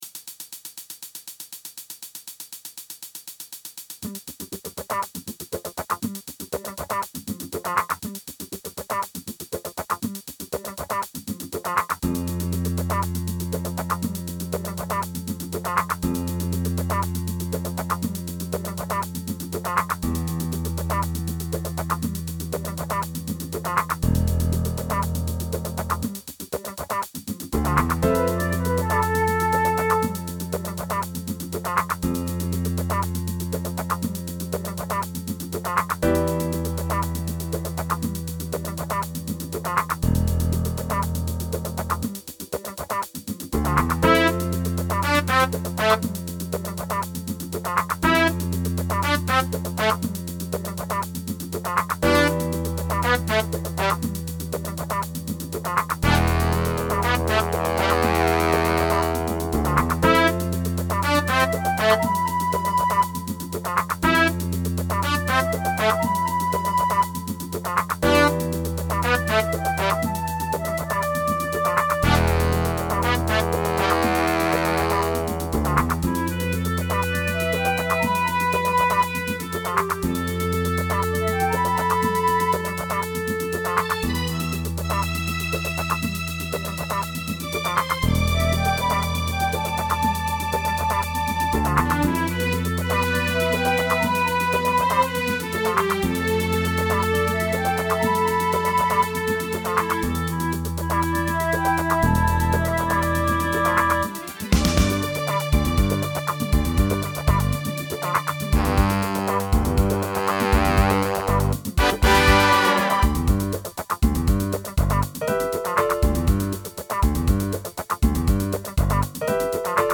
Big Band Vocal